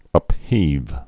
(ŭp-hēv)